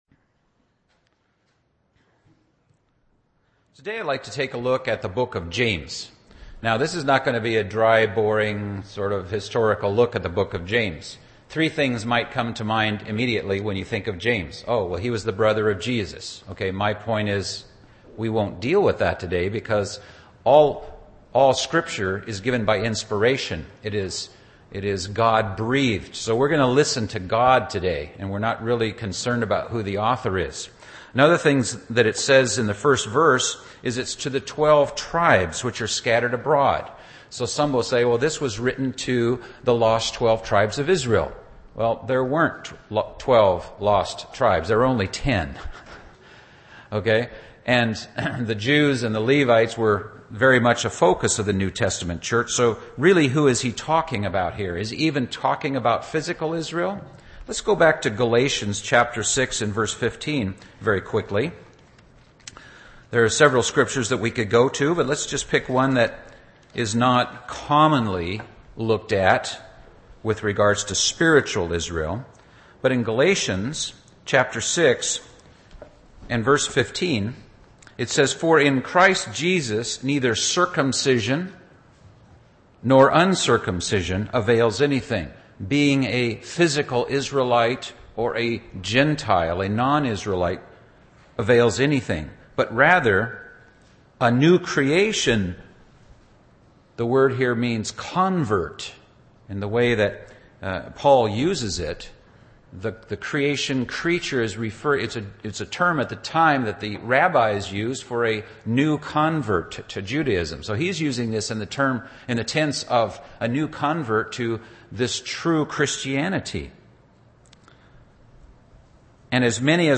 A Bible study of the book of James, looking at our journey as a Christian